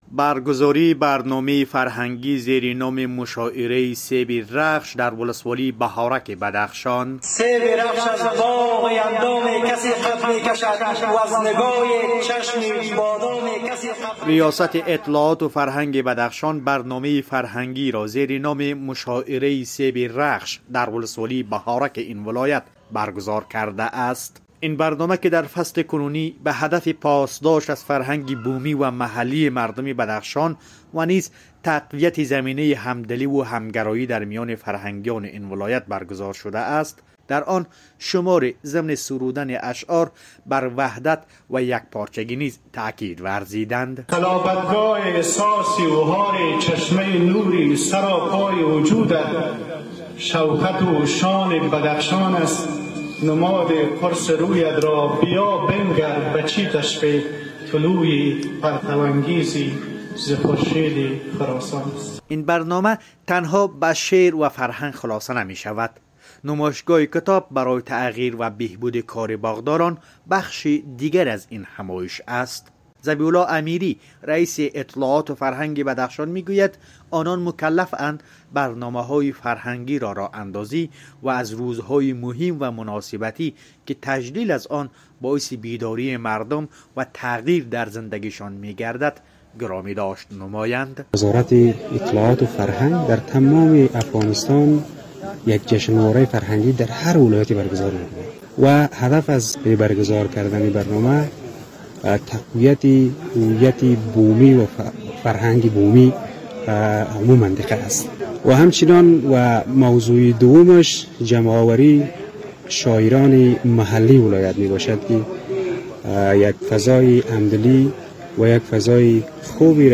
گزارش فرهنگی